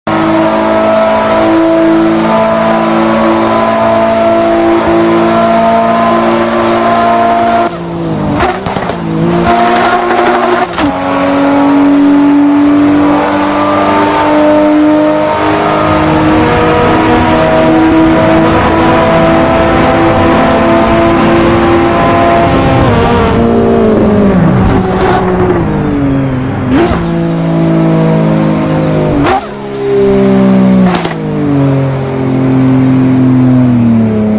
Mitsubishi Evo X Crash💥💥💨💨 Sound Effects Free Download